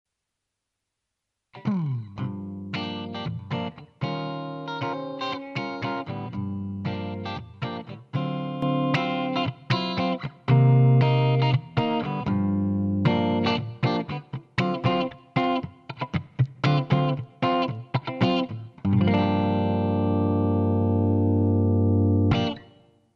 ElectricGtrClean
Le tracce sono state elaborate inserendo/escludendo il compressore in modo da far apprezzare le differenze e il contributo dell’effetto sul suono.
ElectricGtrClean.mp3